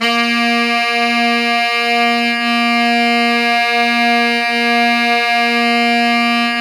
SAX SFA#3X0F.wav